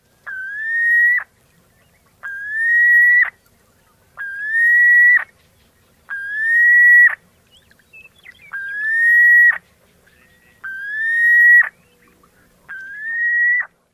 笛鸻求偶鸣叫声